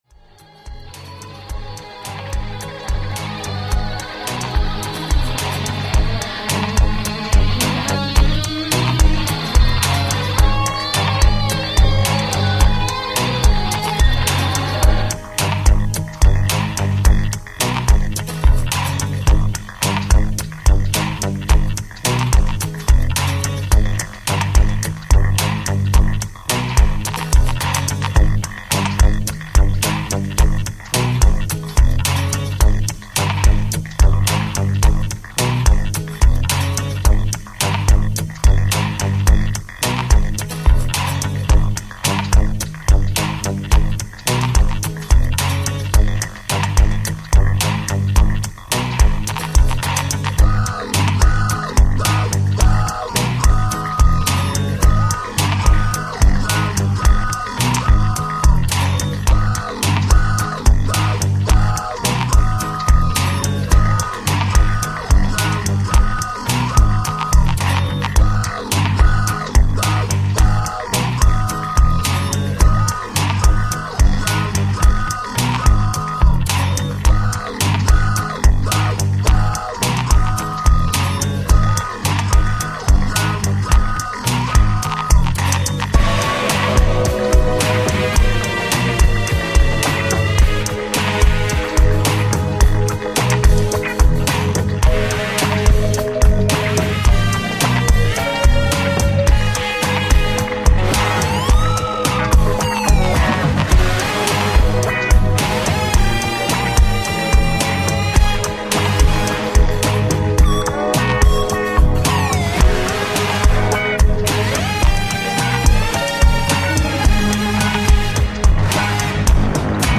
バレアリック系DJも要チェックです！